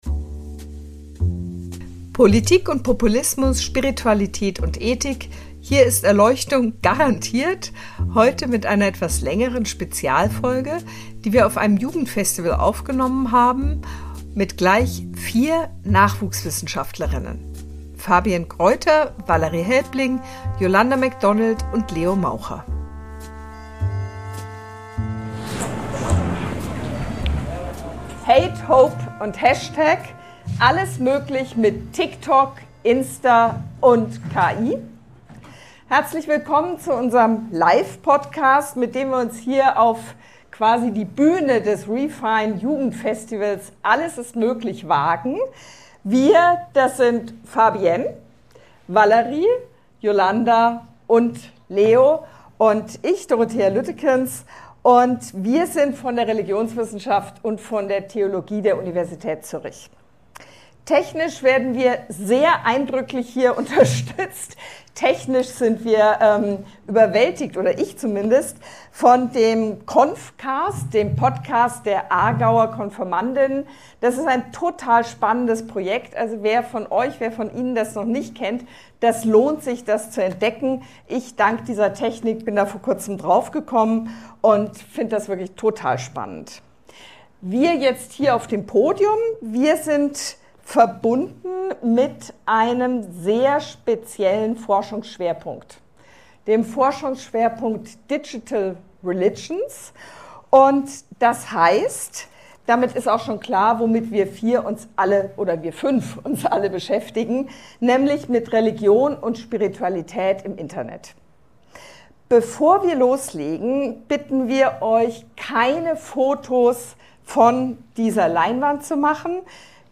Diesen und vielen weiteren Fragen rund um Religion in den sozialen Medien gehen wir in einer «Special Edition»-Episode nach, die live am reformierten Jugendfestival Refine aufgenommen wurde.